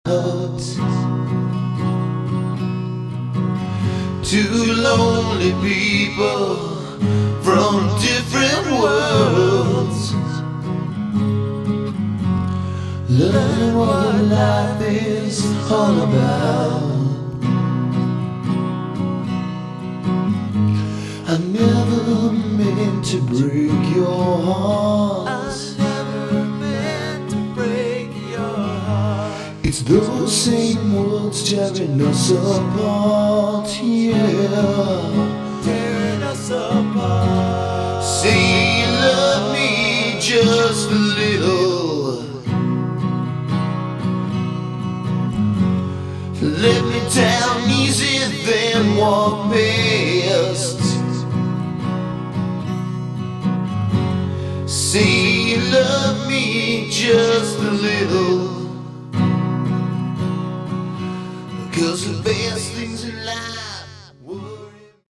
Category: Sleaze Glam / Hard Rock